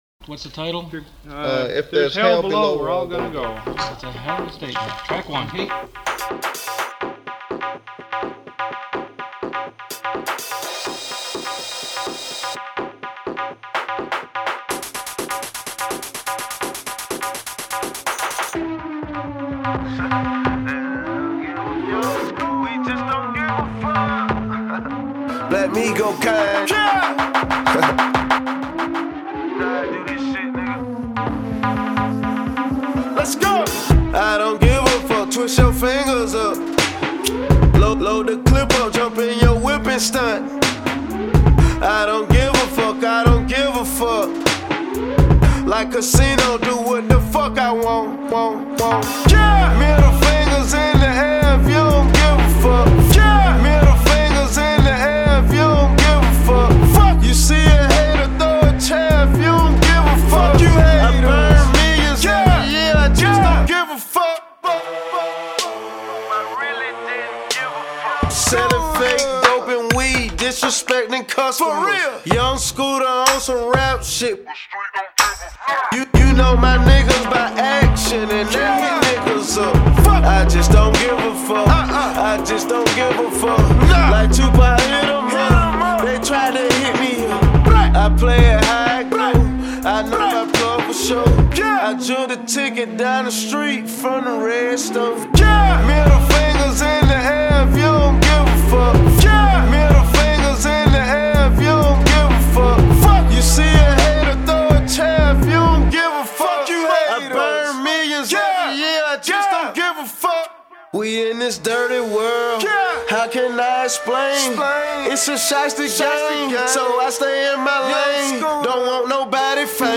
The rest is guitar stuff but that good guitar stuff, take a listen won’t you.